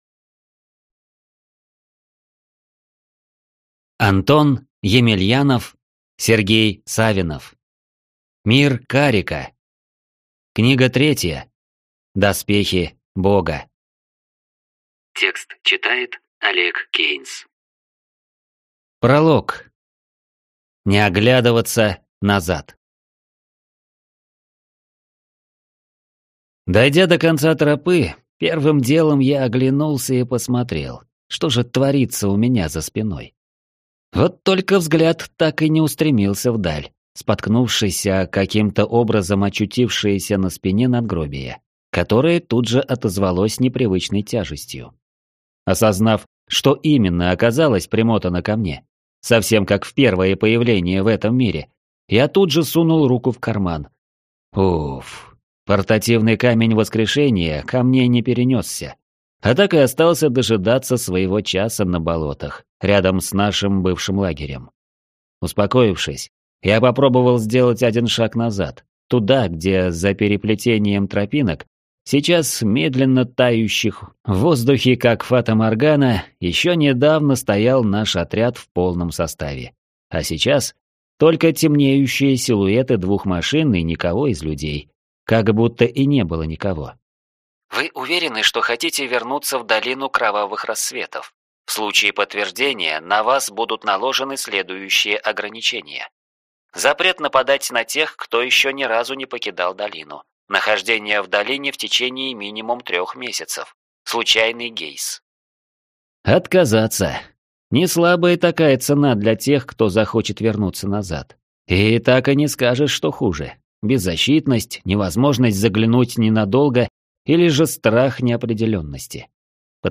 Аудиокнига Мир Карика. Доспехи бога | Библиотека аудиокниг